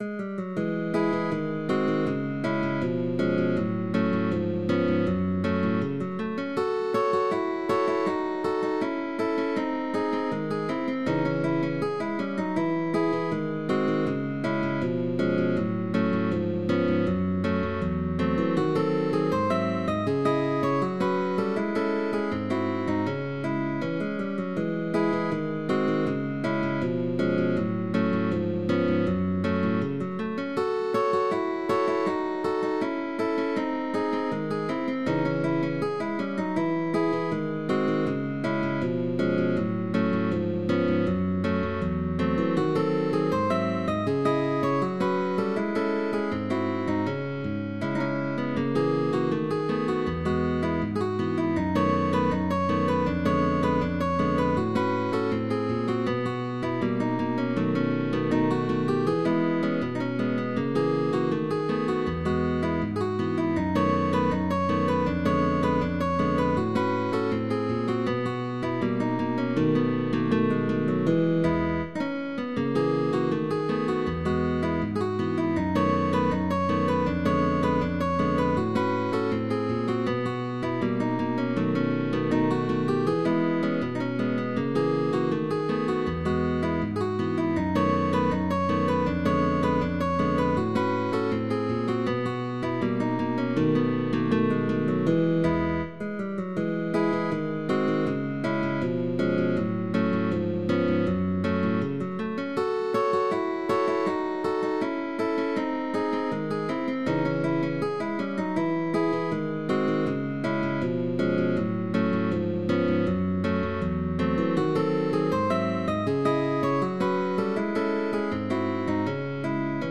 GUITAR DUO